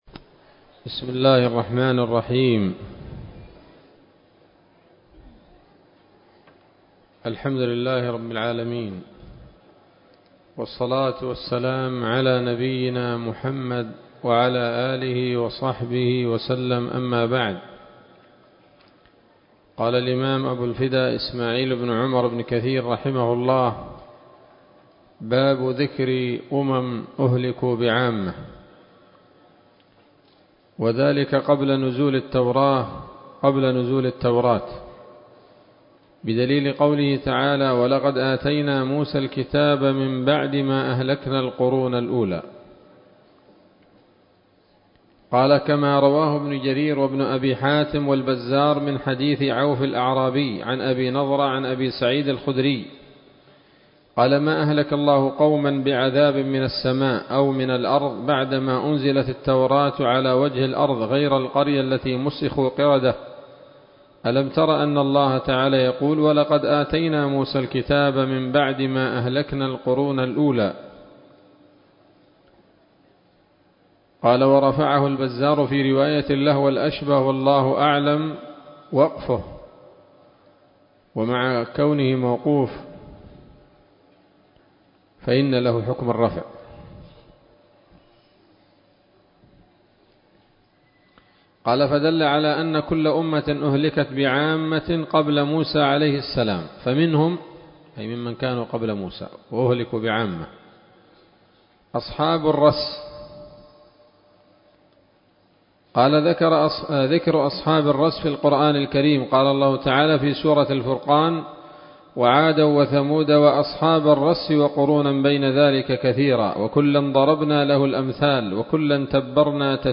الدرس السابع والسبعون من قصص الأنبياء لابن كثير رحمه الله تعالى